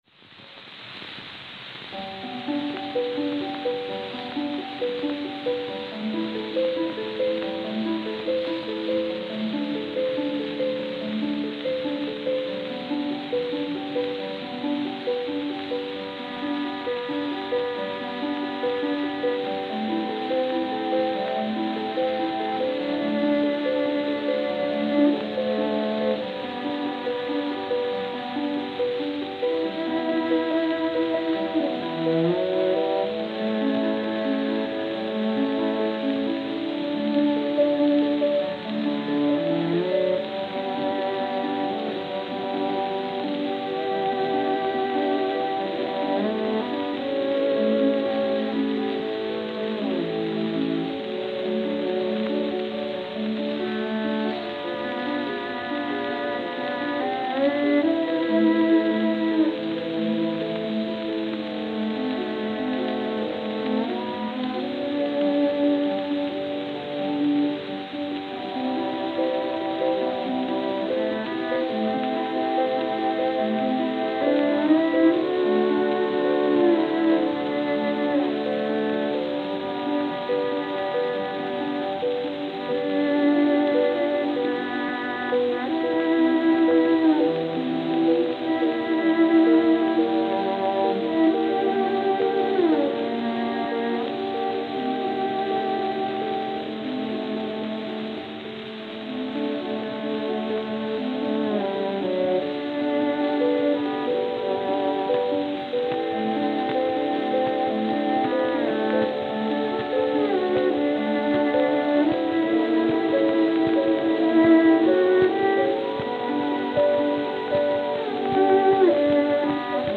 Victor Red Seal 78 RPM Records
Emmy Destinn
New York, New York